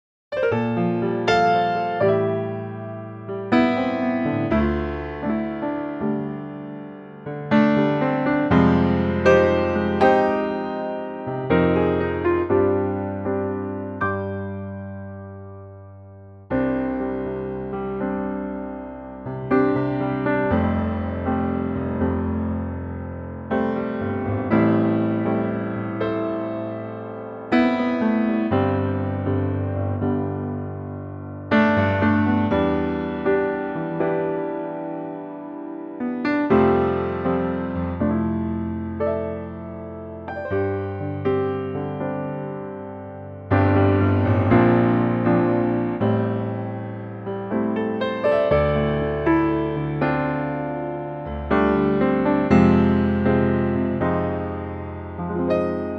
Unique Backing Tracks
4 bar intro and vocal in at 14 seconds
key change included and stays in tempo all the way.
key - C to D - vocal range - G to D (optional G falsetto)
Gorgeous piano only arrangement
(Semitone lower than the original key.)